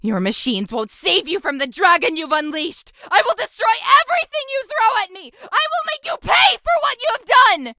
112 KB To show for curious users how Lilac's voice sound when VERY upset. 1
Lilac's_Voice_Sample_2.ogg